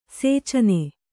♪ sēcana